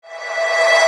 VEC3 Reverse FX
VEC3 FX Reverse 55.wav